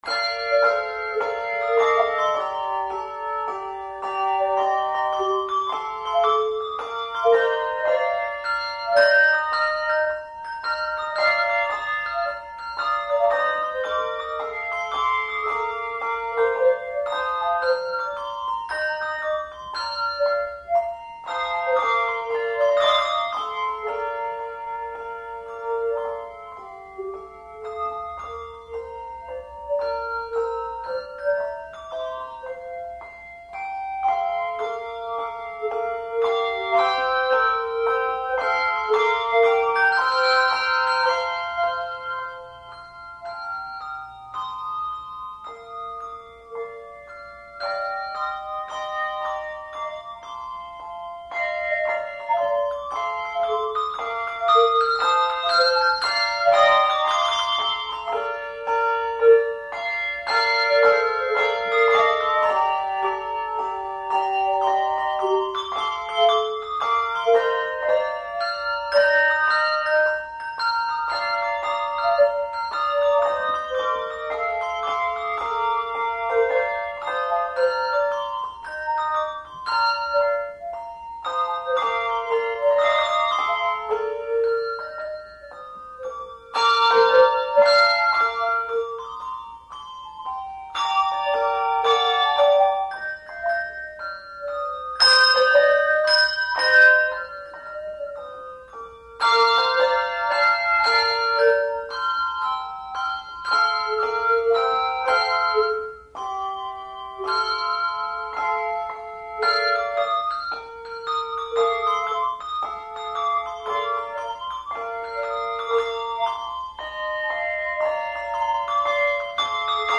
Handbell Quartet
Genre Sacred
No. Octaves 2 Octaves